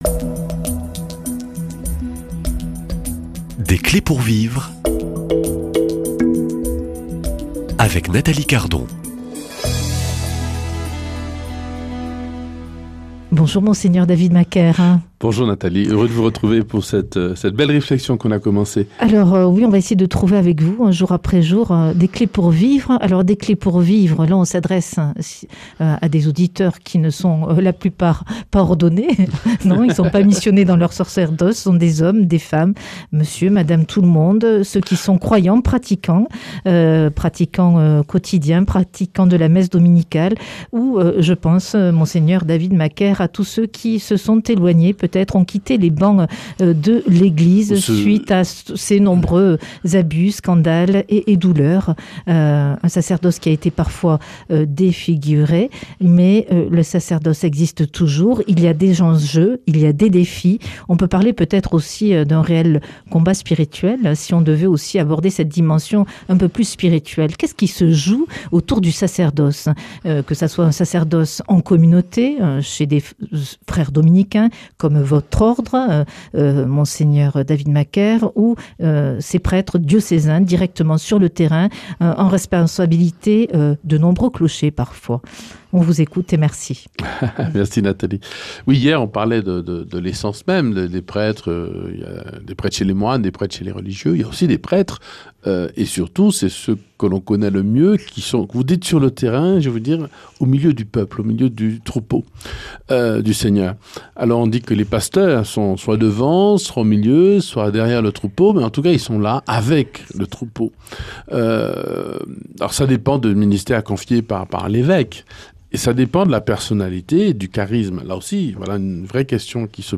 Invité : Mgr David Macaire, ordonné prêtre chez les Dominicains à Toulouse en 2001.